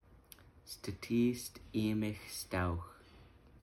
Stah tee-ss ee-mehx-sst-owwhx) – This meaningful phrase translates to “walk beside us” in the beautiful Halq’eméylem language.
Stetis Imexstowx Pronunciation.mp3